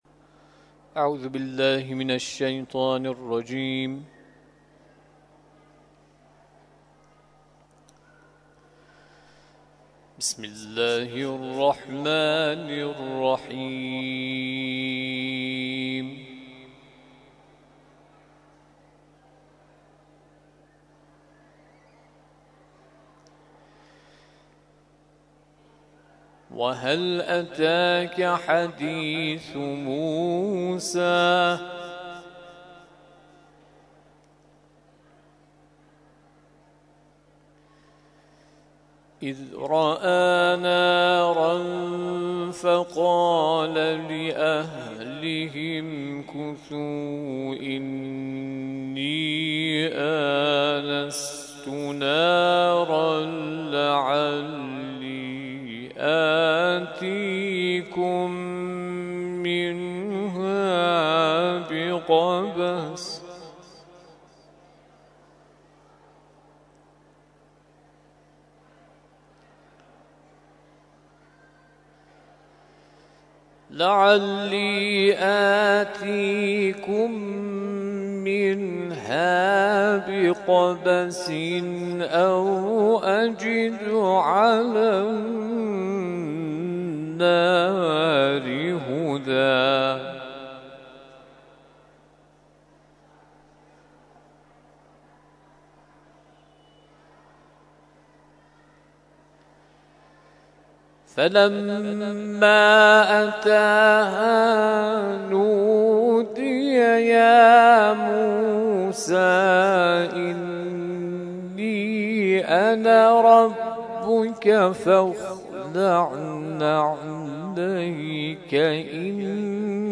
تلاوت مغرب - سوره طه آیات (۹ الی ۴۱) Download